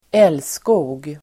Ladda ner uttalet
Folkets service: älskog älskog substantiv (litterärt), love-making [literary] Uttal: [²'el:sko:g] Böjningar: älskogen Synonymer: pippa, samlag, sex Definition: (sexuell) kärlek love-making substantiv, älskog